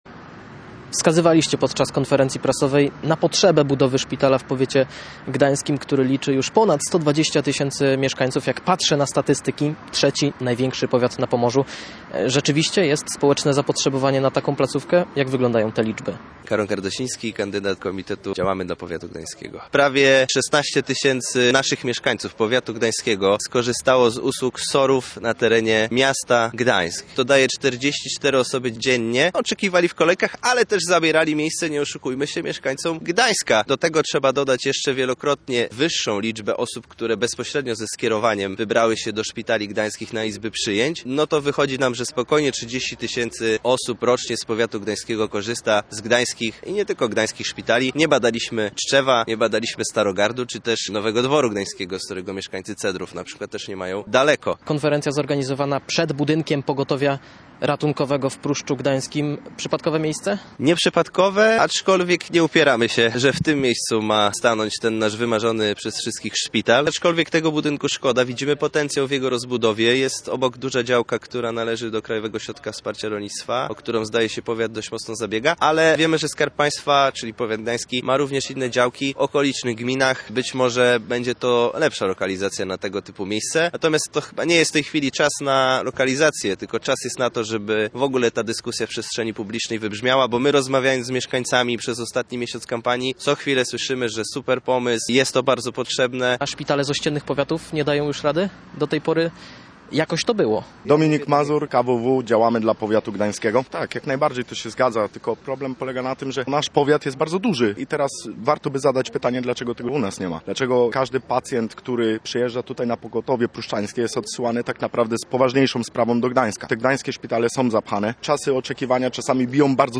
Powiat gdański, trzeci największy na Pomorzu, liczy ponad 120 tysięcy mieszkańców, a w dalszym ciągu nie ma swojego szpitala – wskazywali podczas konferencji prasowej kandydaci do rady powiatu z komitetu wyborczego Działamy dla Powiatu Gdańskiego. Ich zdaniem mieszkańcy potrzebują dostępu do całodobowej opieki zdrowotnej na czele z ortopedią, interną i pediatrią.